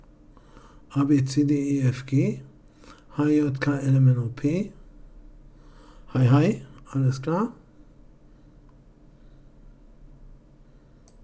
Tascam auf Audac_direkt Test 1.mp3